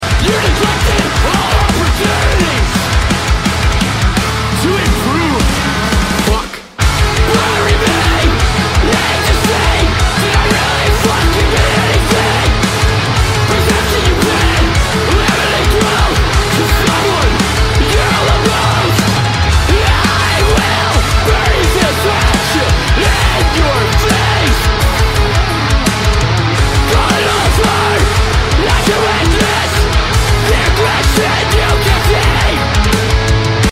Melodic Hardcore from Edmonton, Alberta 🇨🇦 Check it out!